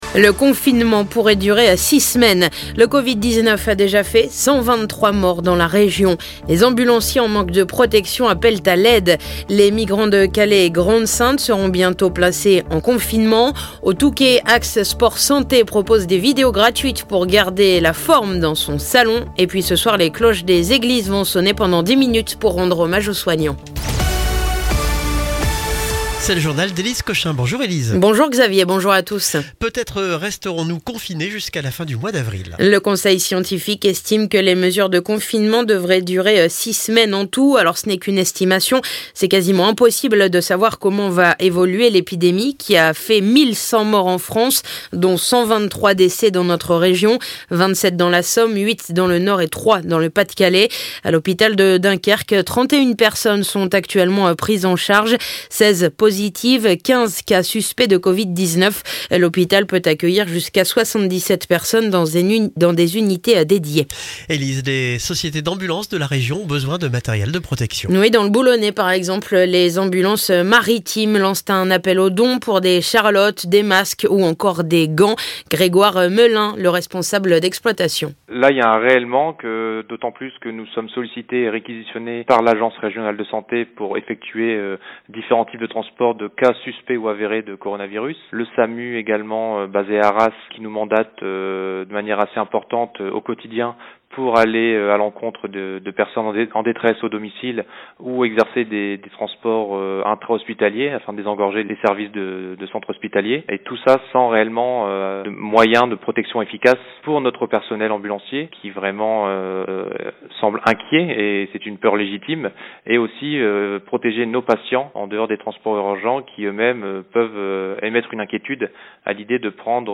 Le journal du mercredi 25 mars